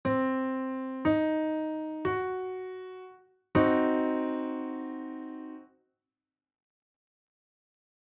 特徴的な響きを持つディミニッシュコードを把握する
コードの構成音を鳴らした後に、コードが鳴ります。
なんだか、怪しい響きのするコードですね。
Cdim.mp3